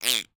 latest / assets / minecraft / sounds / mob / dolphin / idle2.ogg